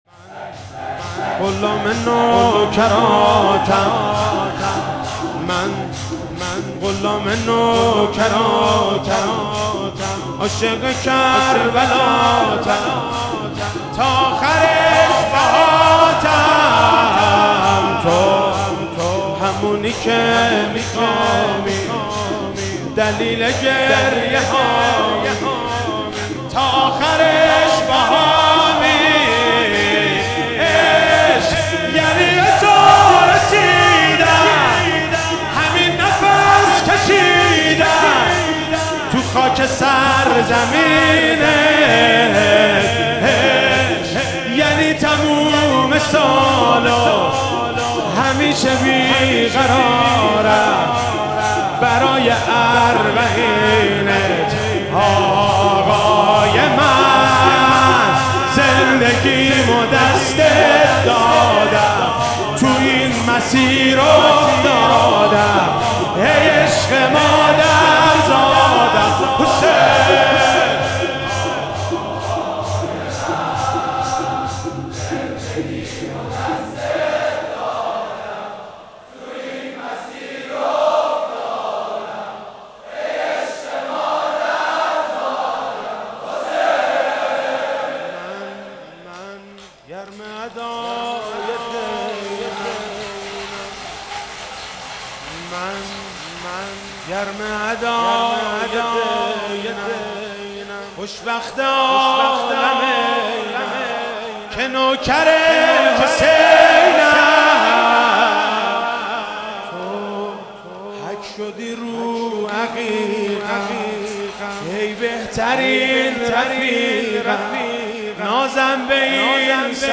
شب عاشورا محرم 97 - شور - من غلام نوکراتم عاشق کربلاتم